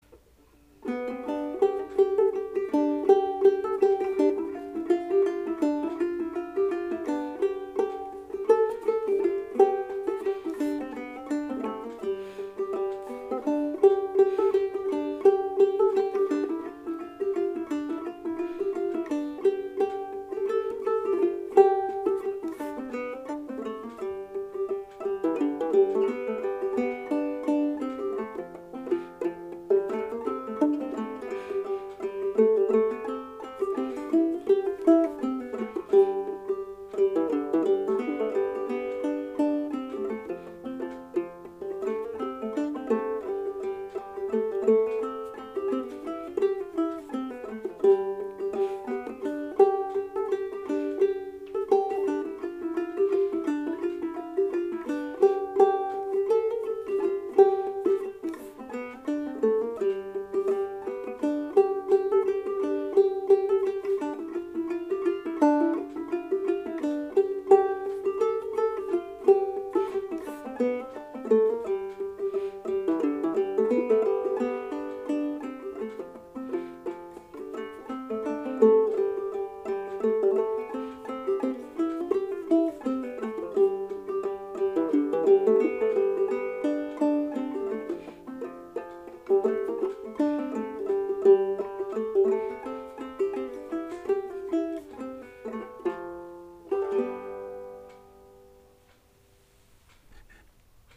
Banjo Hangout Newest 100 Clawhammer and Old-Time Songs
Another member-generated podcast of great banjo tunes.